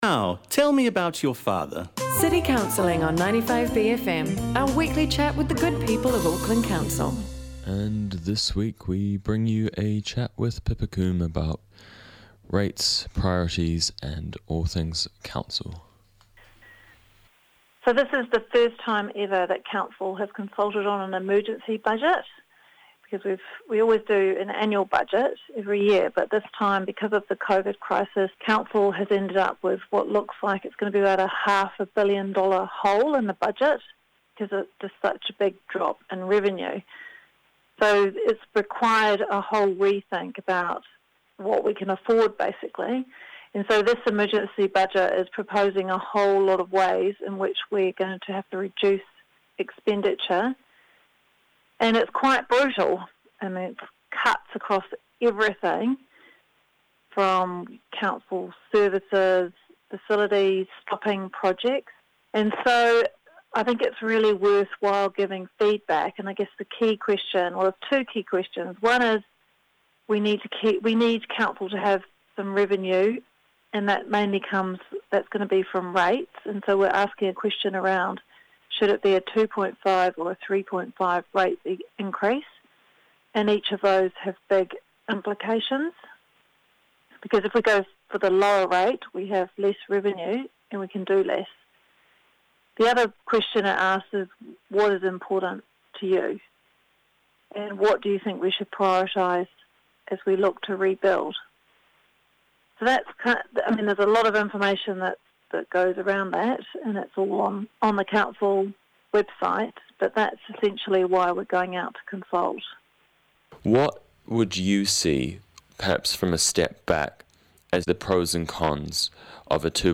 This week on City Counselling, we talk to Cr Pippa Coom about rates, priorities and homelessness.